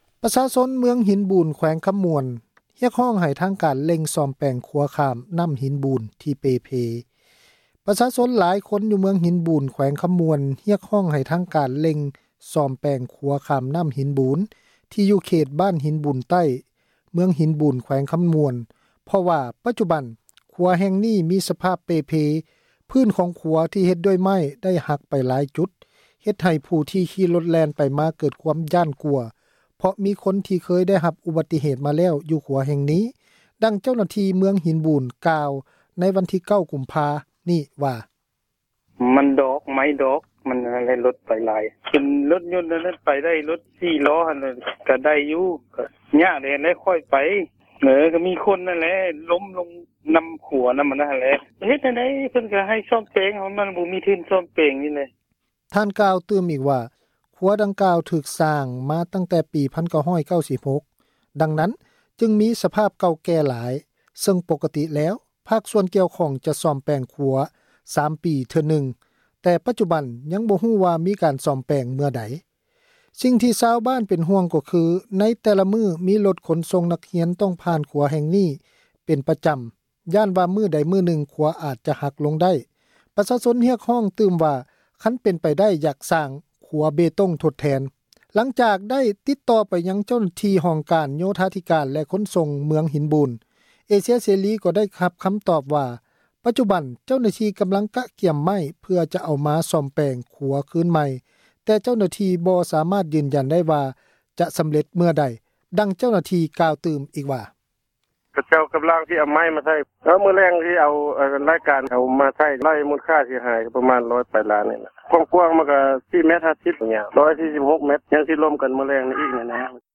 ຂົວຂ້າມນໍ້າຫີນບູນ ເປ່ເພ — ຂ່າວລາວ ວິທຍຸເອເຊັຽເສຣີ ພາສາລາວ